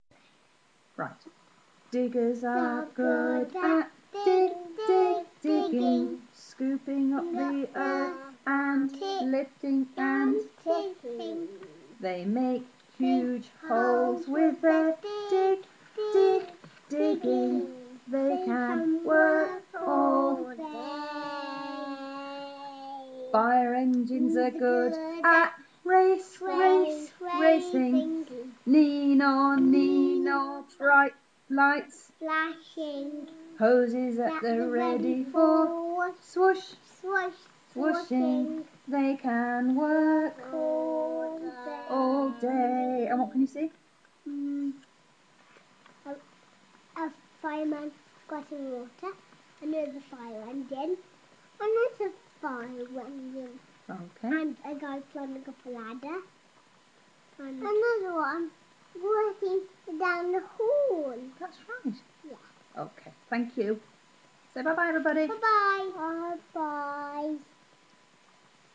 Singing Margaret Mayo's Dig, Dig, Digging